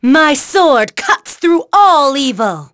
Aselica-Vox_Skill3.wav